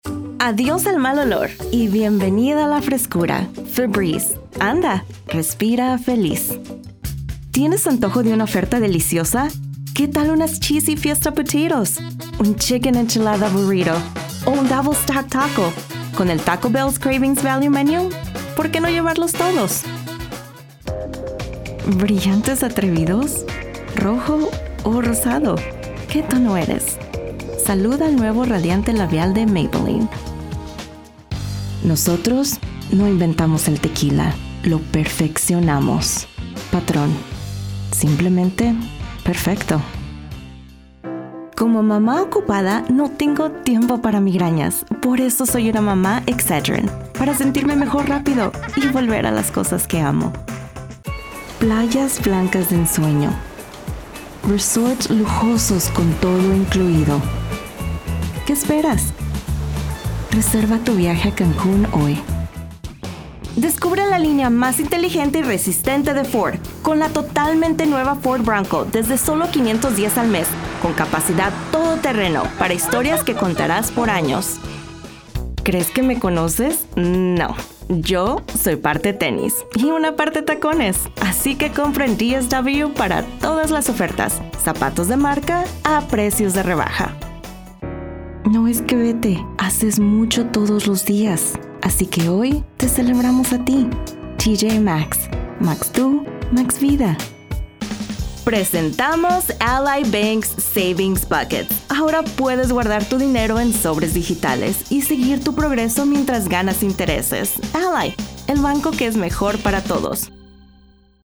Spanish Commercial Demo
Spanish - Mexican, Spanish - Neutral